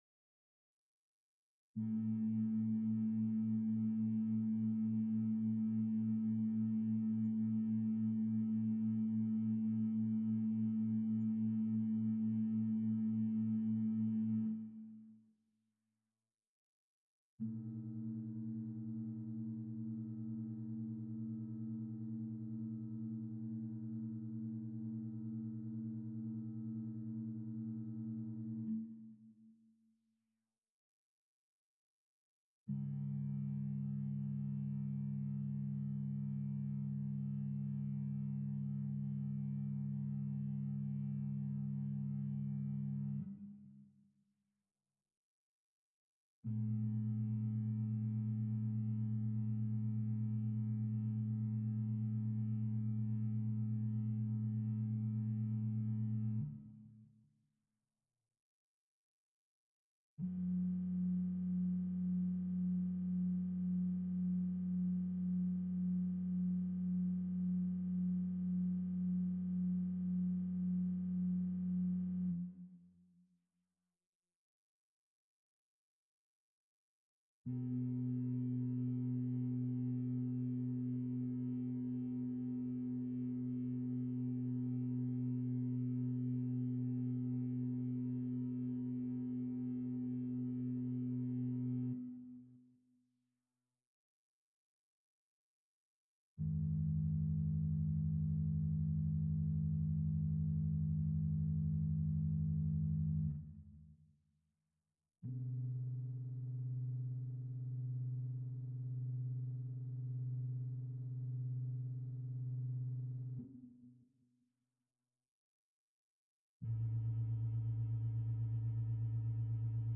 无人机 " 恼人的无人机 2
描述：在理性中通过光谱操纵电子琴声音创建的无人机。
Tag: 数字 电子 声音设计 噪声 毛刺